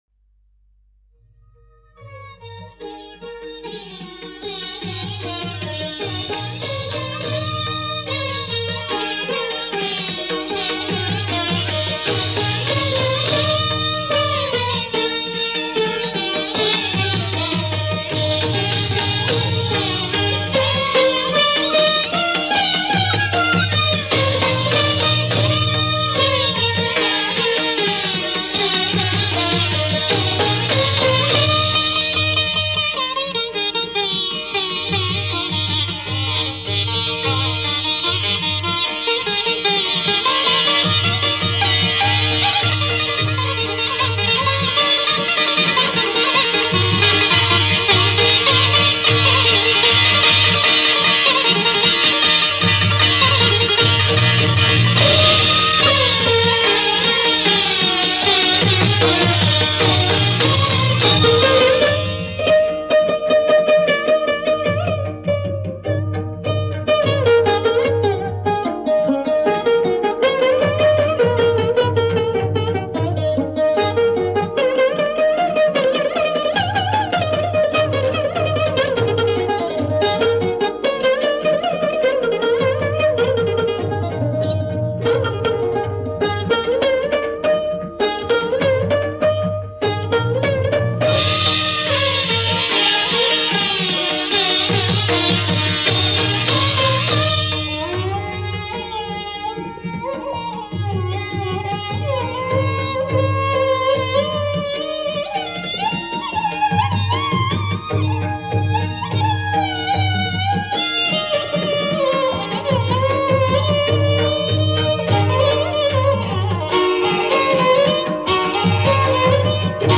(Instrumental)
Hindi Movie